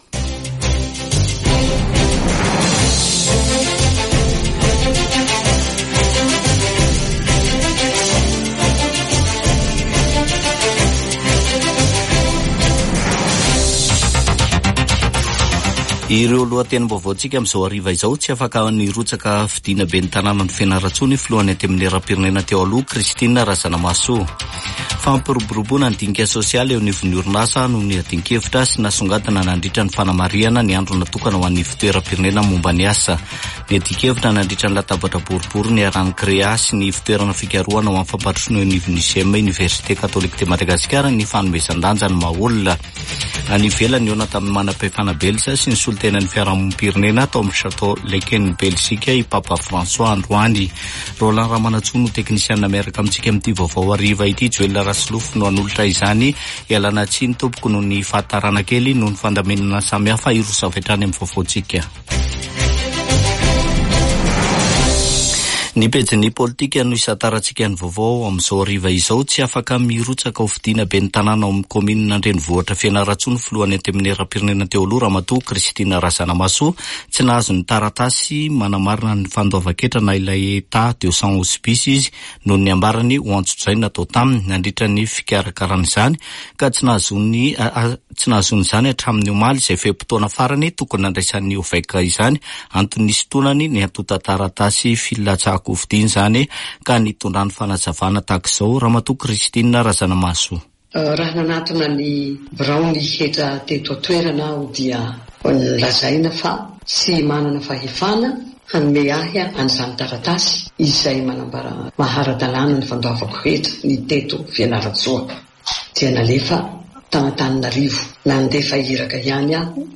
[Vaovao hariva] Zoma 27 septambra 2024